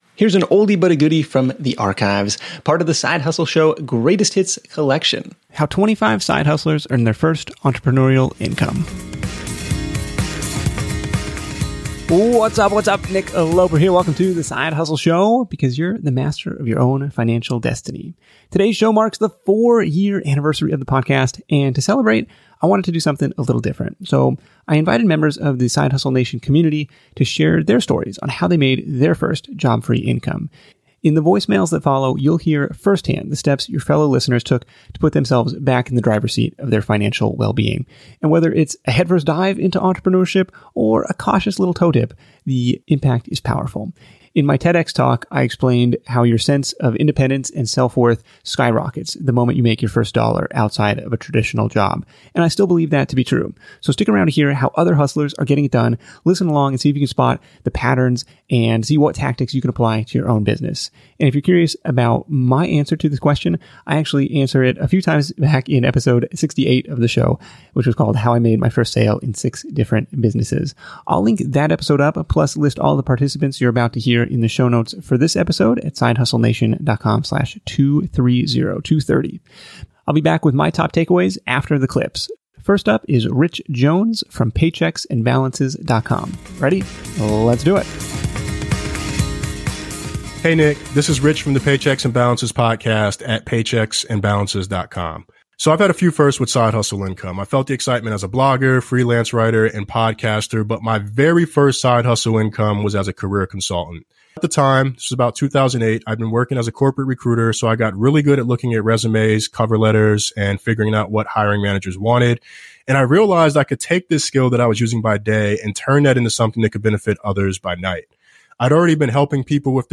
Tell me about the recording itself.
I asked people to tell me how they first started making money on the side, and got 25 different voicemails!